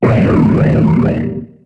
Cri de Tutankafer dans Pokémon HOME.